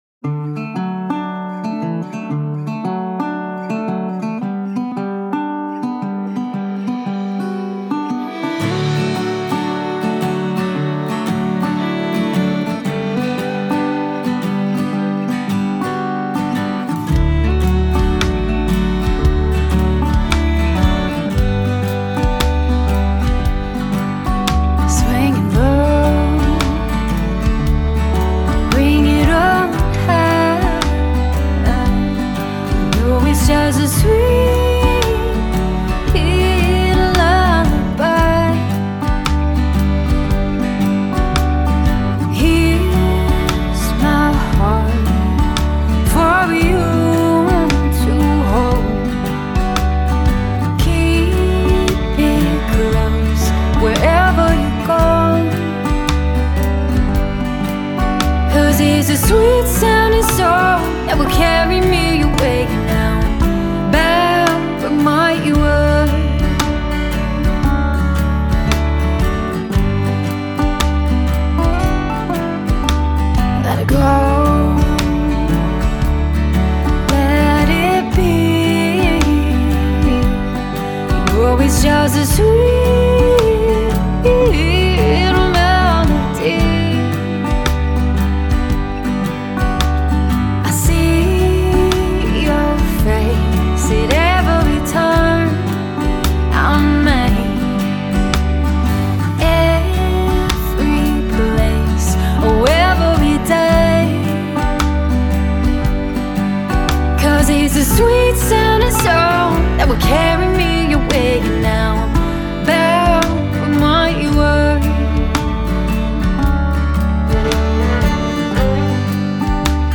folk-Americana